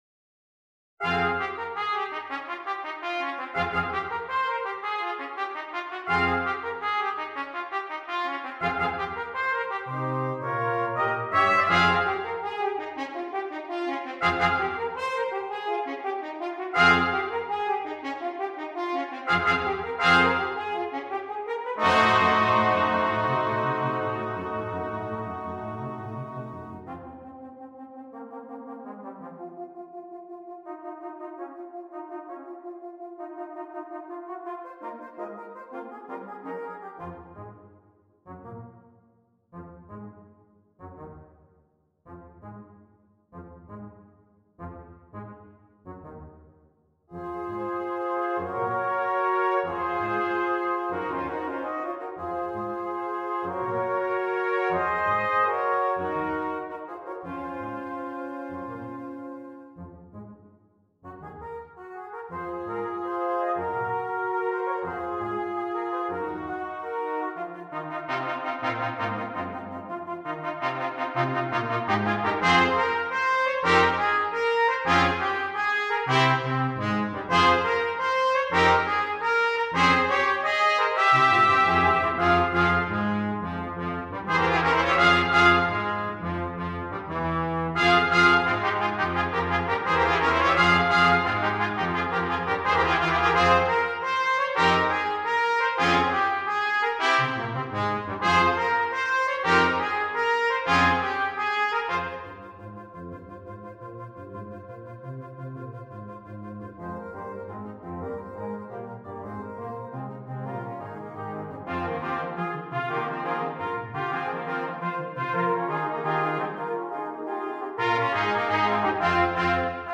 Brass Quintet
each with its own character.